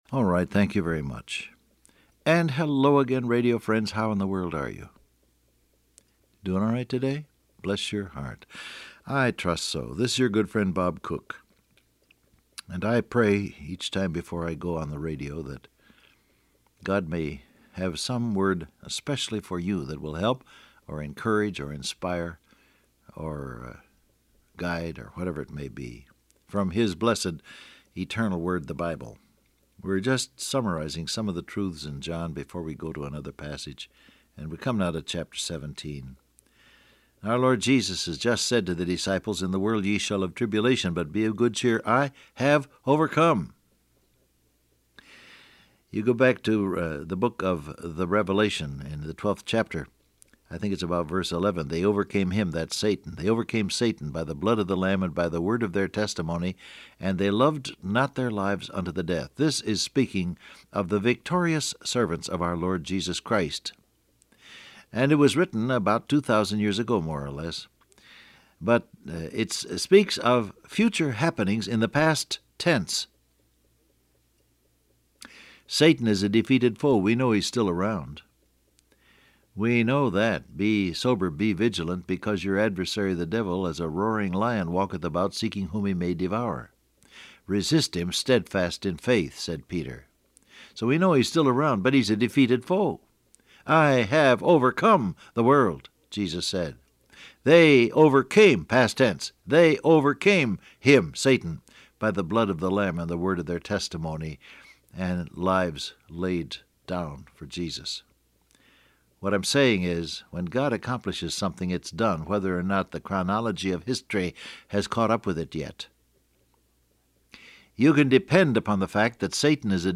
Download Audio Print Broadcast #6998 Scripture: John 17 Topics: Glorify God , I Overcome , Ive Finished , Manifest Your Name Transcript Facebook Twitter WhatsApp Alright, thank you very much.